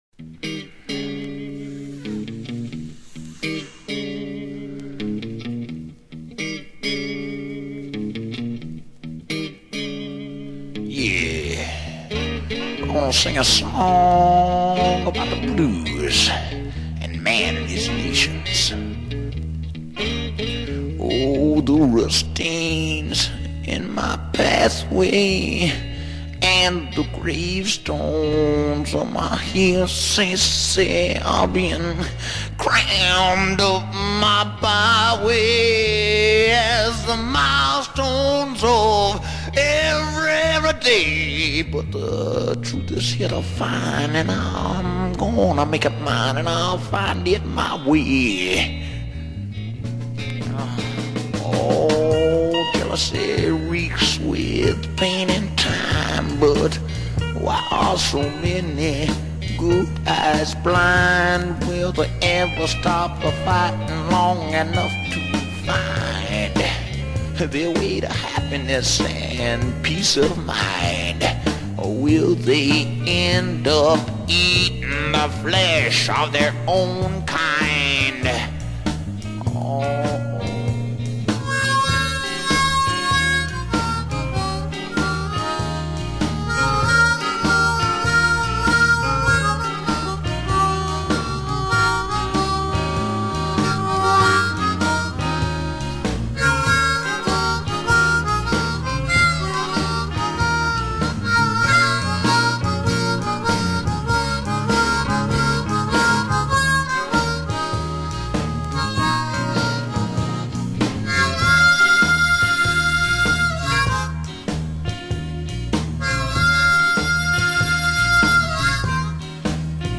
studio album
Southern Rock
vocals
guitar